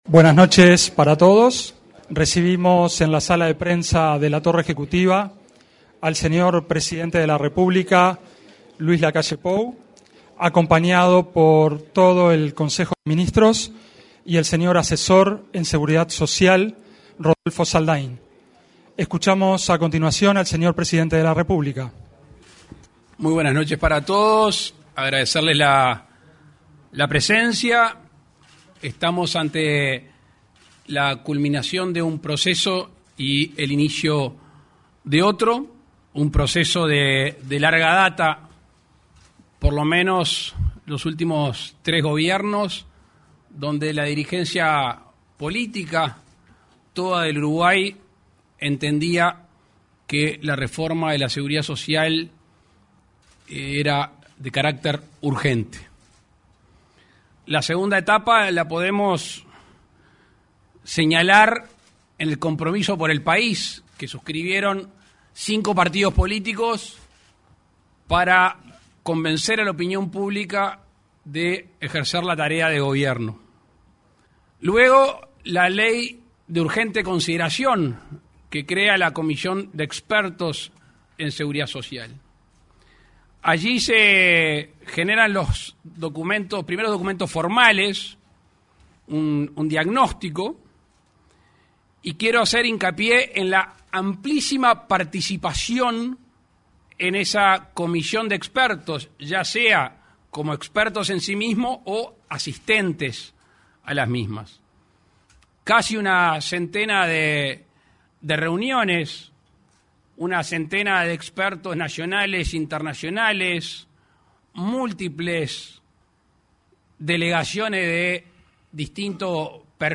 Conferencia de prensa del presidente de la República, Luis Lacalle Pou
El presidente de la República, Luis Lacalle Pou, brindó una conferencia de prensa acerca del proyecto de reforma de la seguridad social.